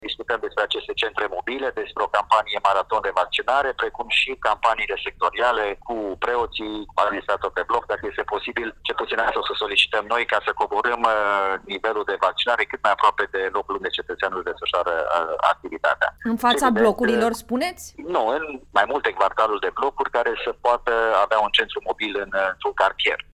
Edilul orașului Cluj-Napoca, cu explicații la Europa FM: